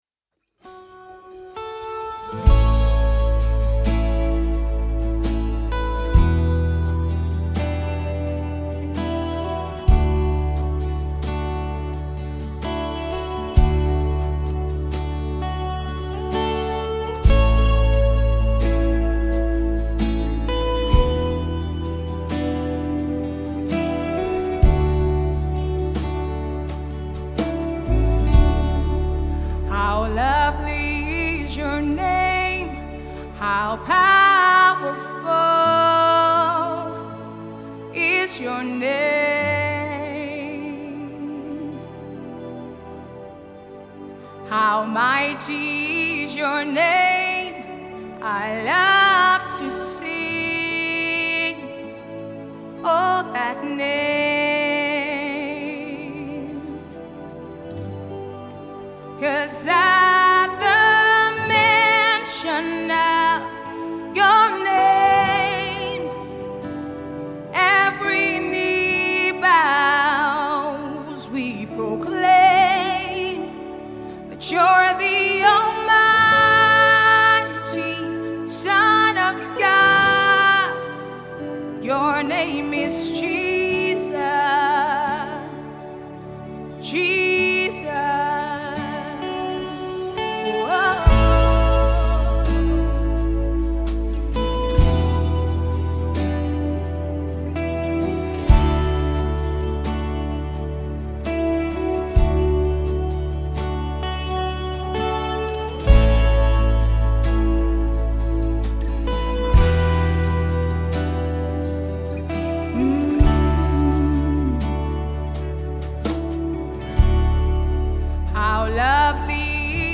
a song of worship and adoration